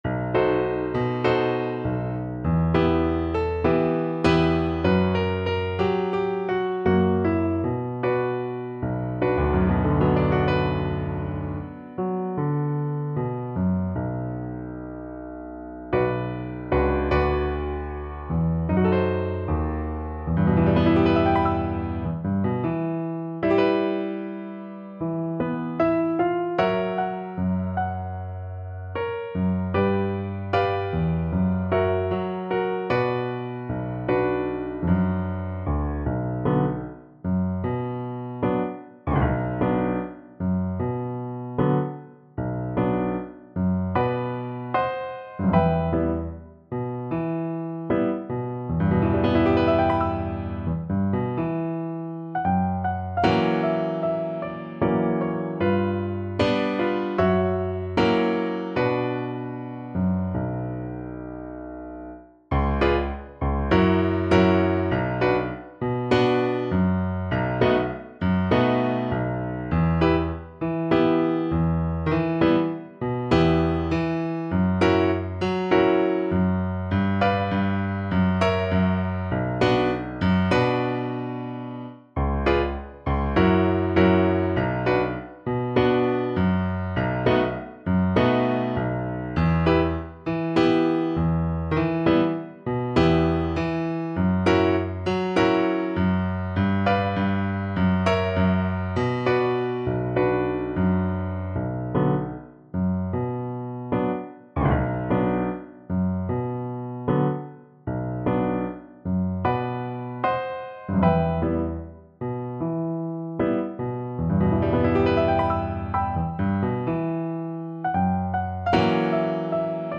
Moderato =c.100